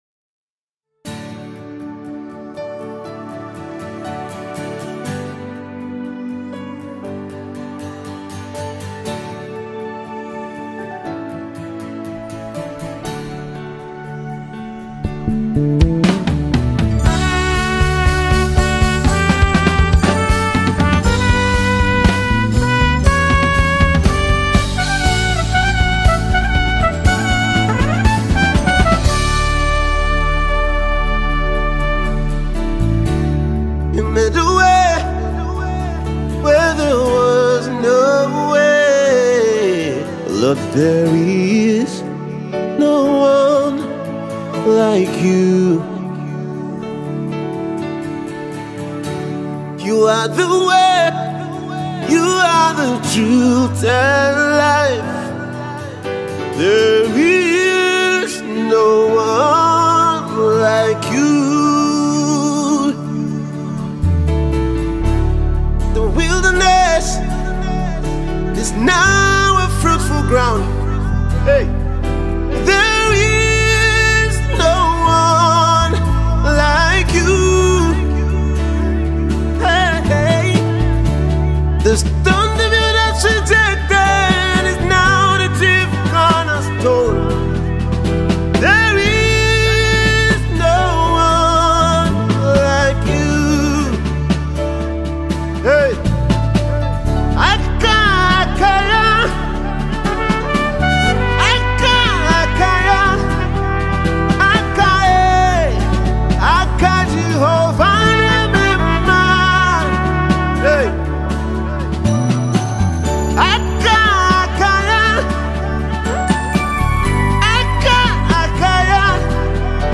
Nigerian worship minister
spirit-lifting record